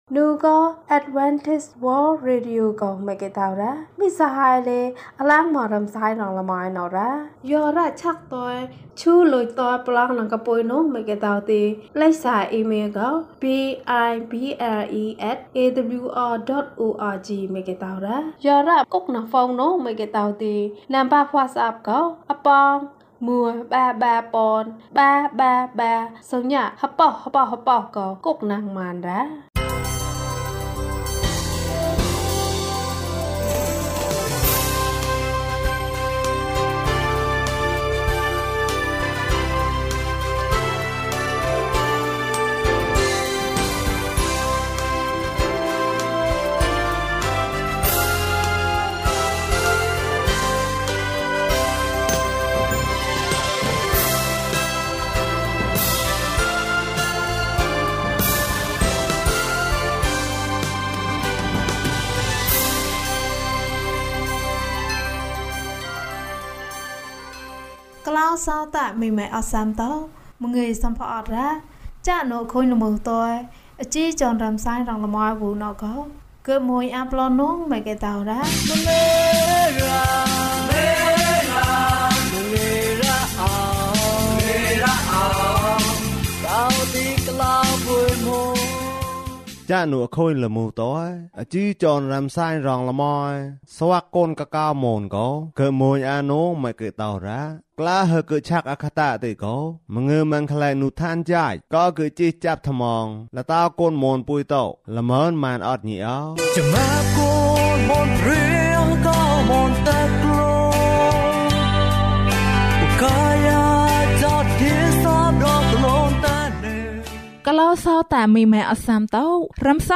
သမ္မာကျမ်းစာဇာတ်လမ်း။၀၁။ ကျန်းမာခြင်းအကြောင်းအရာ။ ဓမ္မသီချင်း။ တရားဒေသနာ။